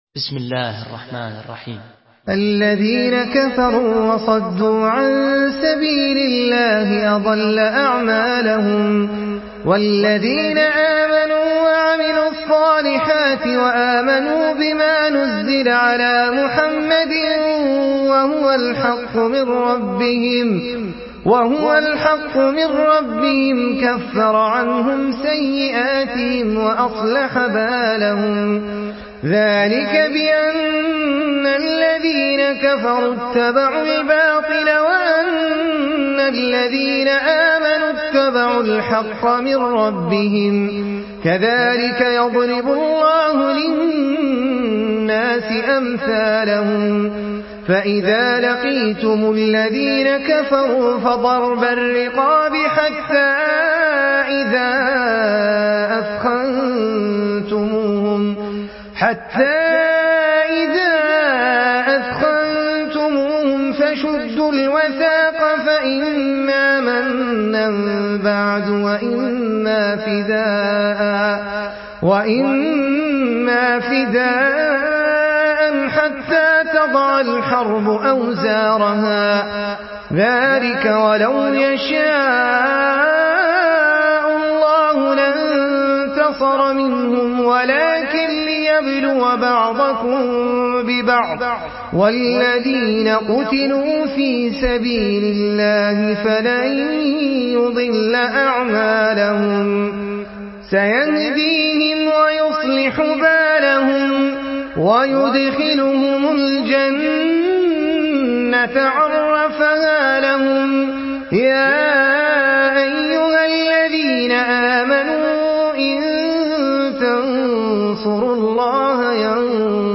Surah Muhammad MP3 in the Voice of Ahmed Al Ajmi in Hafs Narration
Murattal Hafs An Asim